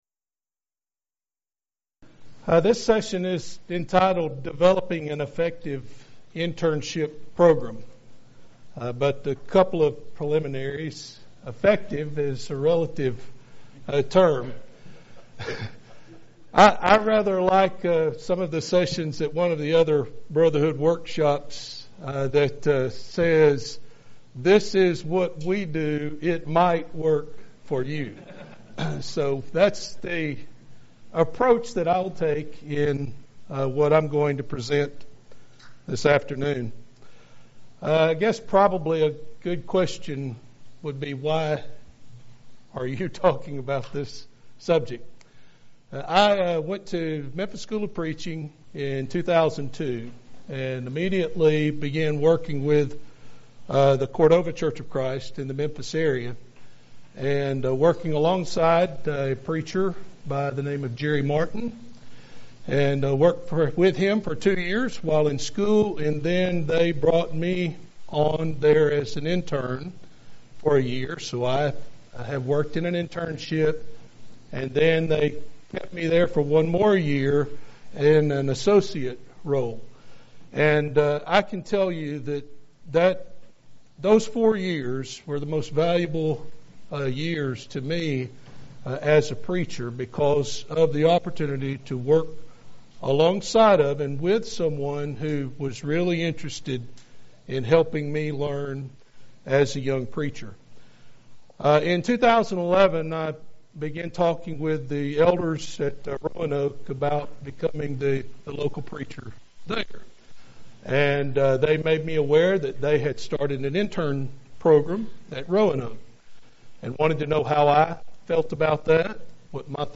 Event: 2019 Focal Point Theme/Title: Preacher's Workshop
lecture